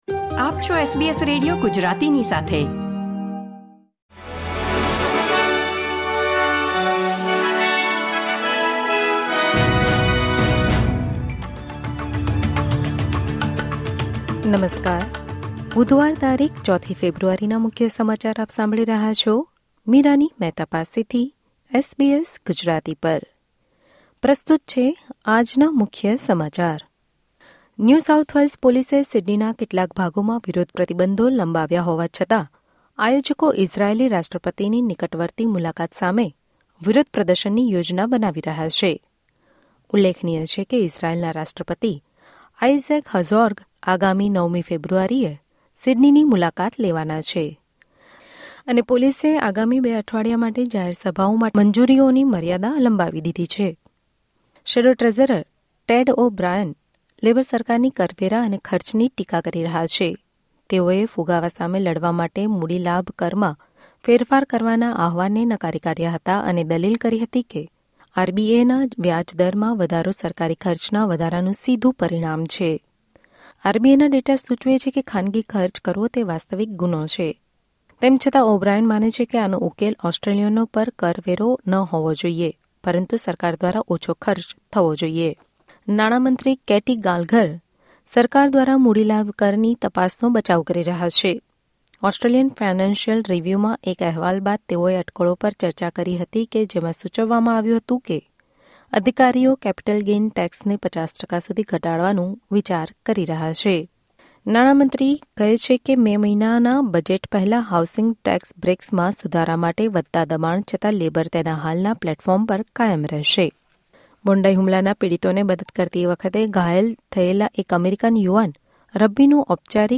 Listen to the latest Australian news from SBS Gujarati